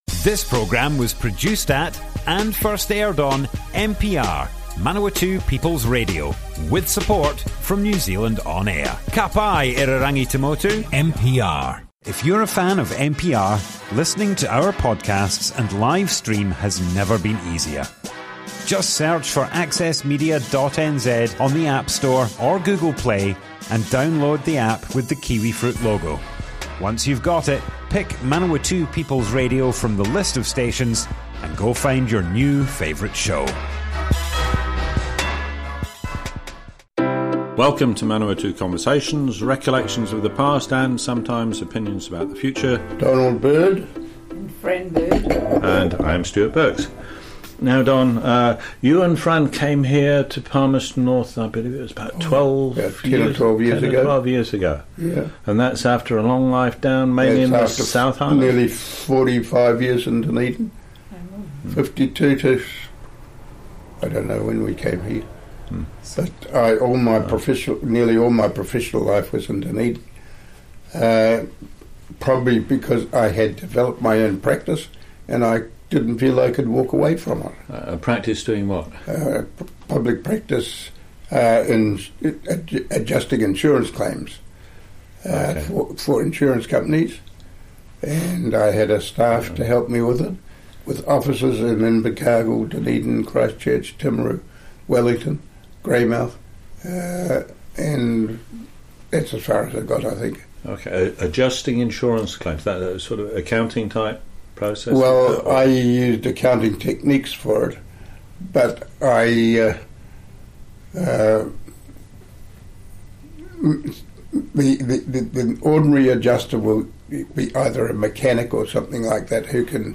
Manawatu Conversations More Info → Description Broadcast on Manawatu People's Radio, 11th February 2020.
oral history